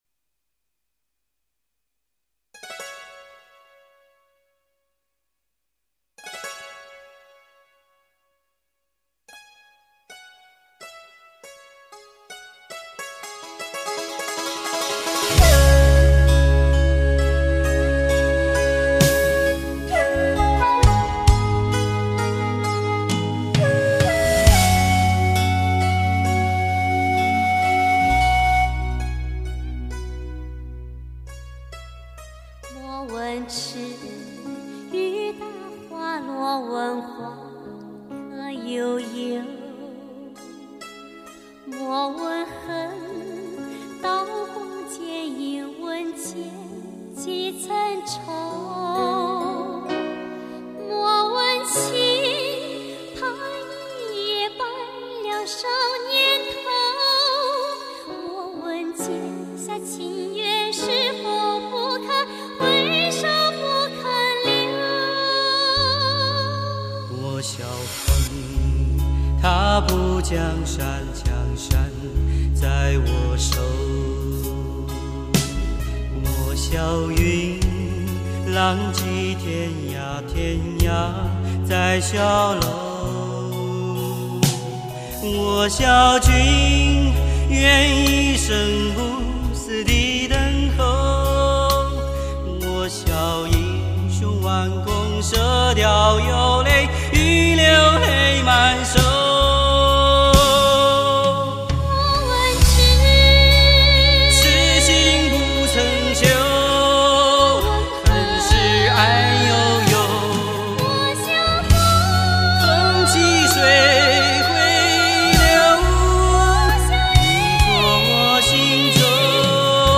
音乐类别：游戏原声
忧国忧民义薄云天的剑侠，生离死别荡气回肠的情缘，特色浓郁的民族音乐，惊险曲折的故事情节，给玩家们留下了深刻的印象。